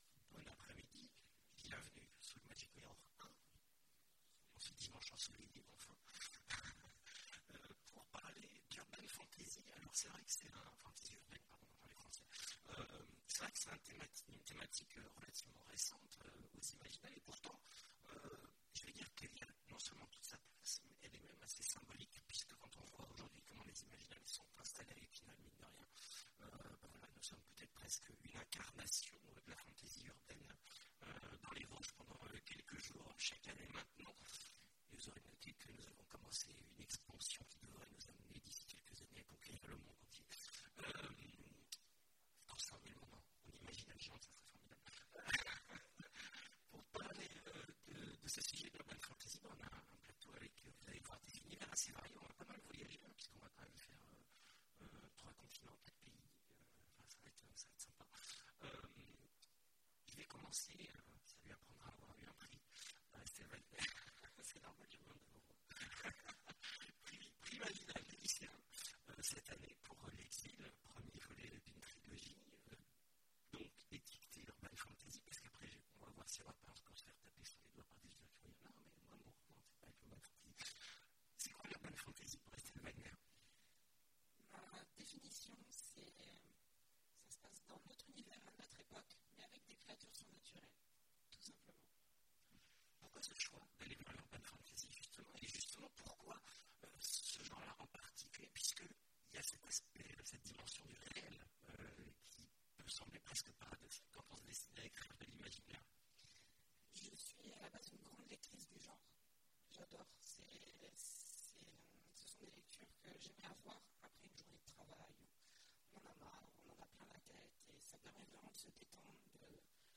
Imaginales 2017 : Conférence La fantasy urbaine… une lecture de plaisir